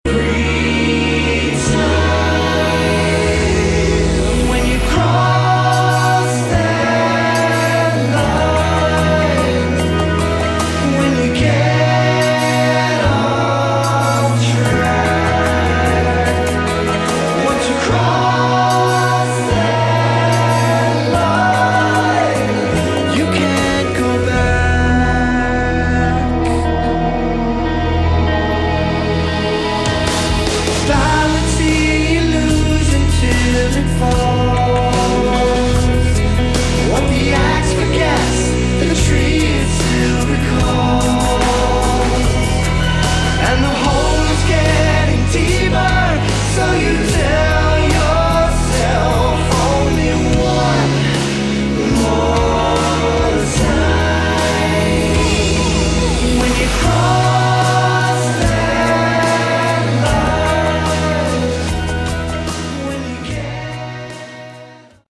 Category: Melodic Rock
vocals
guitar, bass, keyboards
drums